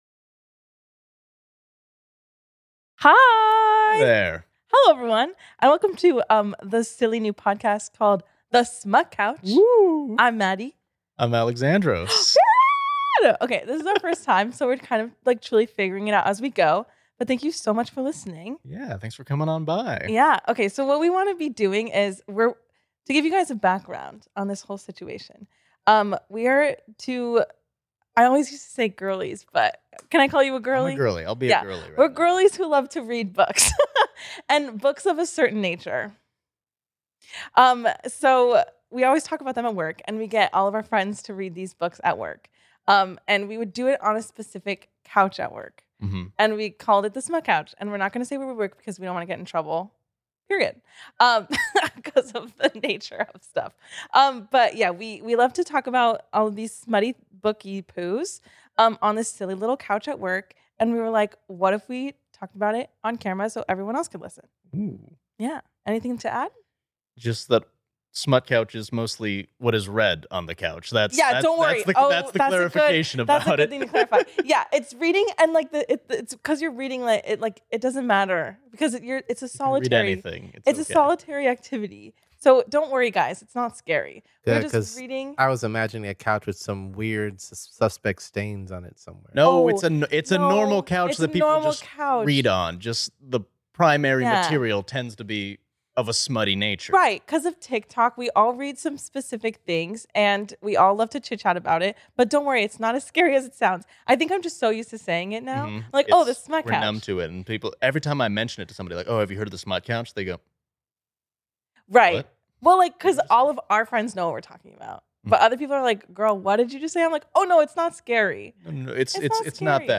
" a laid-back podcast where two work buddies turned besties spill the tea on their love for books.